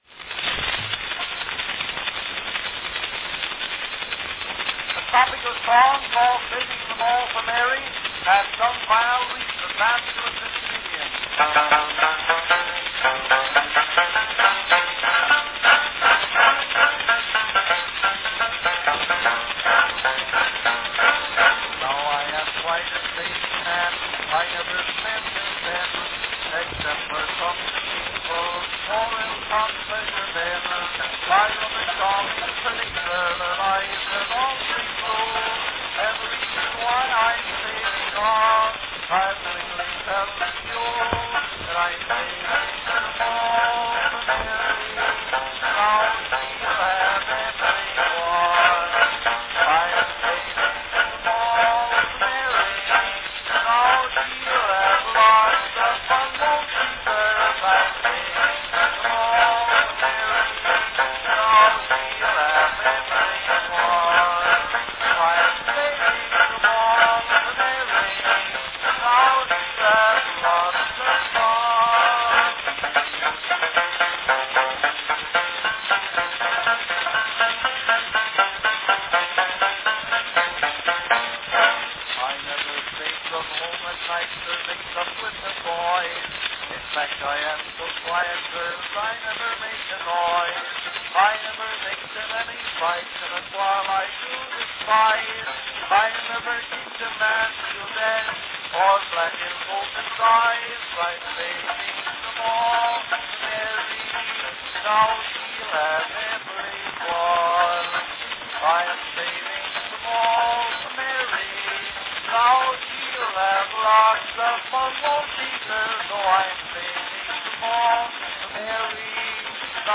A very early banjo & song recording
Category Topical song with banjo
With the stray string pluck prior to the announcement, various 'thunks', and forward sound to the banjo, this master recording packs a lot of personality.   As with most early master cylinder recordings, this is best heard through headphones.
This recording was made on a so-called "cuff"-length cylinder.